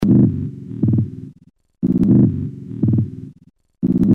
心跳声
描述：以每分钟60次的速度记录典型的人类心跳。 用Tascam DR07 Mk.II麦克风录音。
标签： 心脏 听筒 心脏跳动 心跳
声道立体声